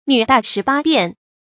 女大十八變 注音： ㄋㄩˇ ㄉㄚˋ ㄕㄧˊ ㄅㄚ ㄅㄧㄢˋ 讀音讀法： 意思解釋： 指女子在發育成長過程中，容貌性格有較多的變化。